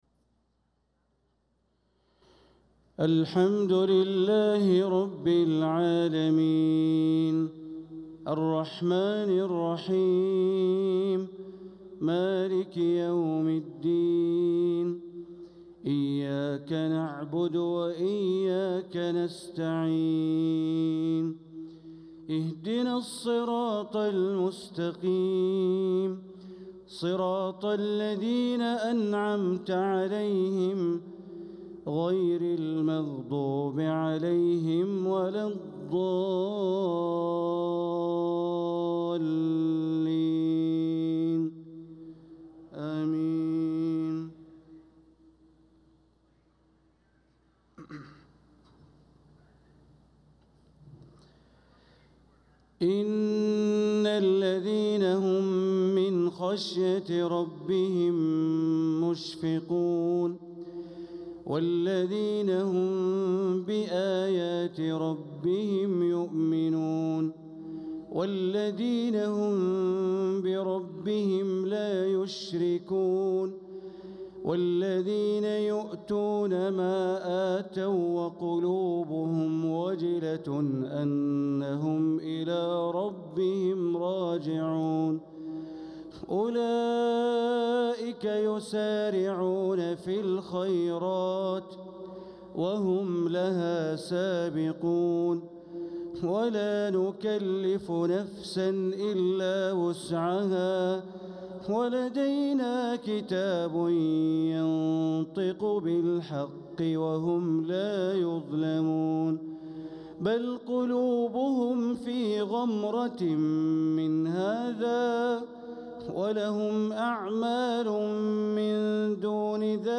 صلاة العشاء للقارئ بندر بليلة 6 صفر 1446 هـ
تِلَاوَات الْحَرَمَيْن .